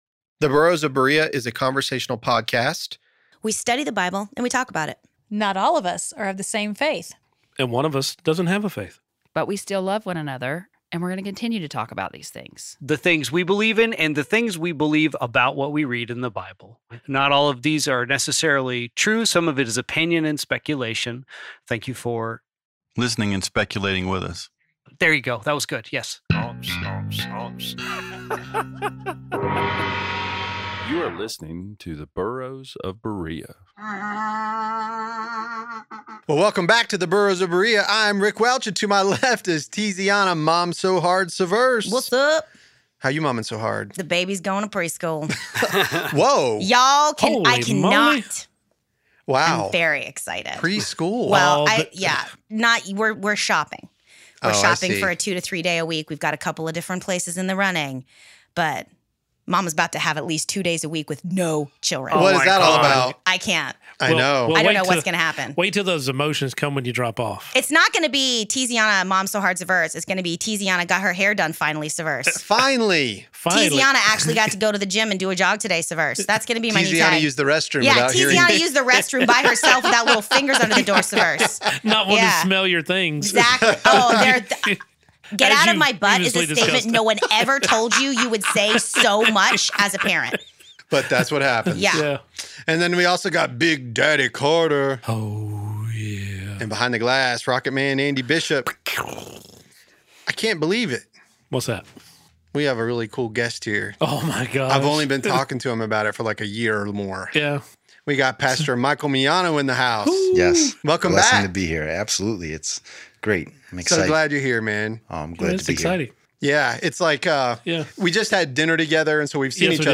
The Burros of Berea is a conversational podcast. We study the Bible and we talk about it.